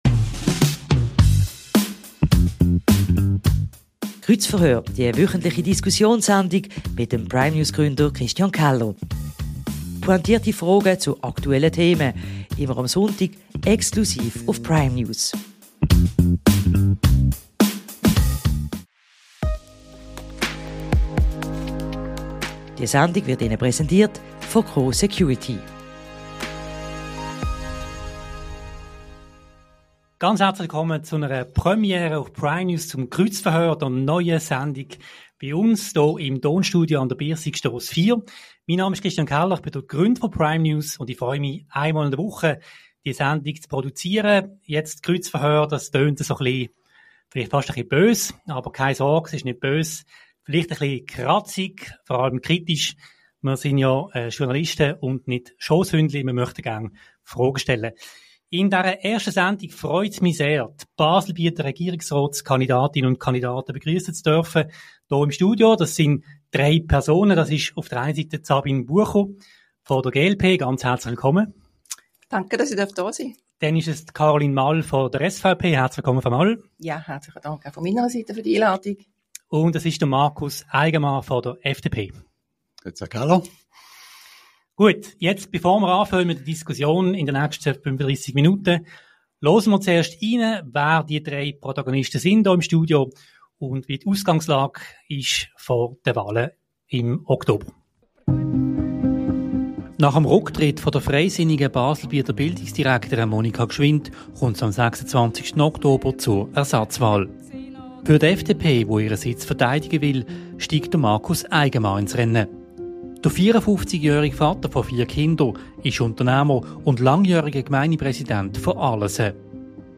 Kreuzverhör mit den drei Baselbieter Regierungskandidaten ~ Kreuzverhör Podcast